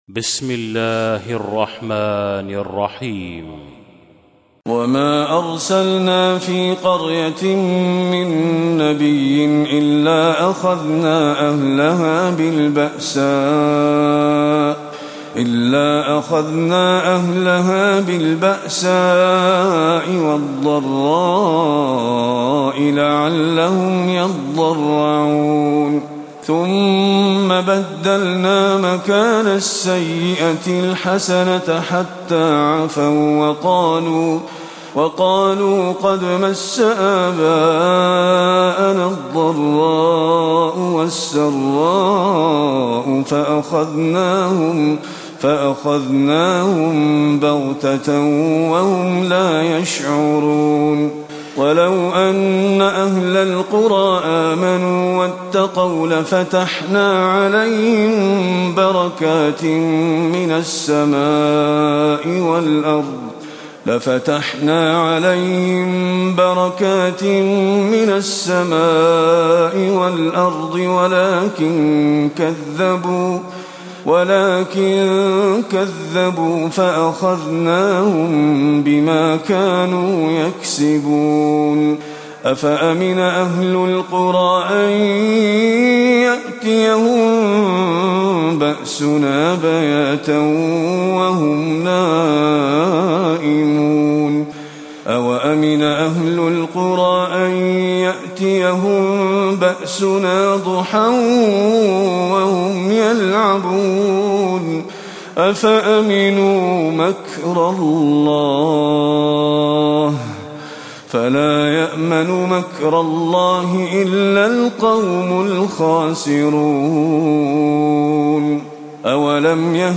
تلاوة سورة الأعراف من آية 94 إلى آية 188
تاريخ النشر ١ محرم ١٤٣٧ هـ المكان: المسجد النبوي الشيخ: فضيلة الشيخ محمد خليل القارئ فضيلة الشيخ محمد خليل القارئ سورة الأعراف (94-188)